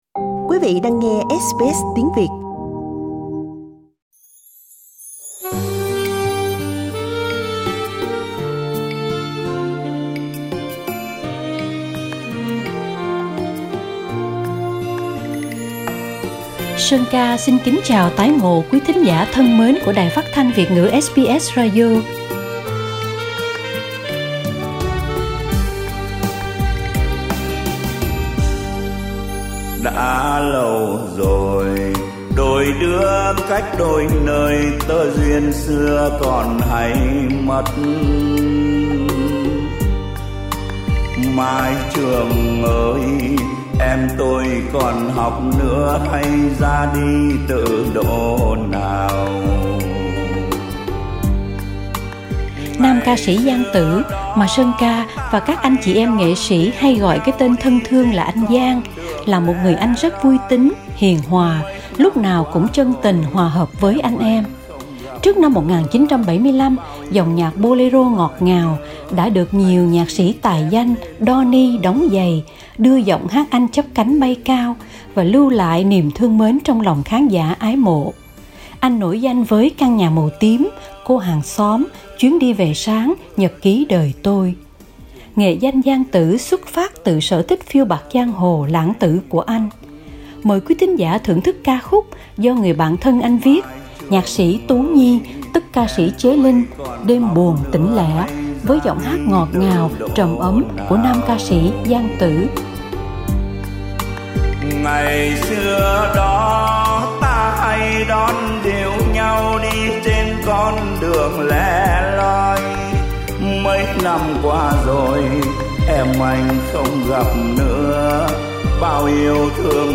1970 với dòng nhạc boléro ngọt ngào qua các ca khúc như